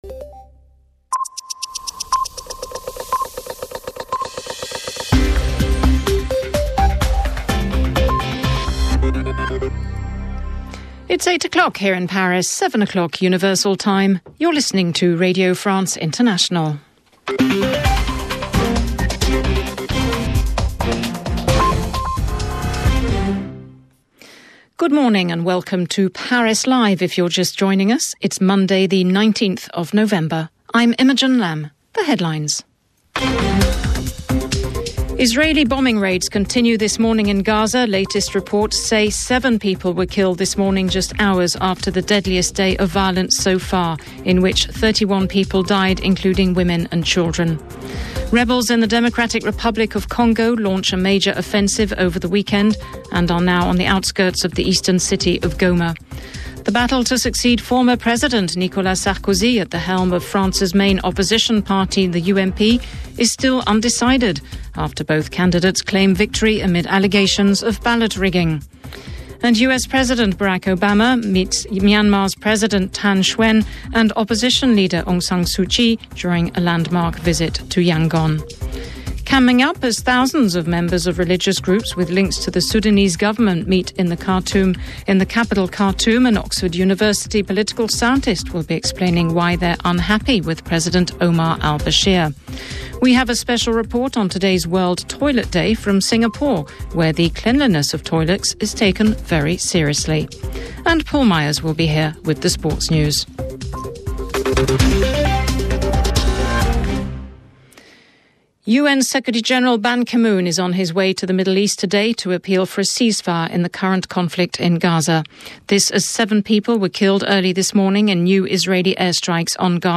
Missiles In Gaza - Death In Aleppo - No Party Unity In Paris - November 19, 2012 - News from Radio France International English Service.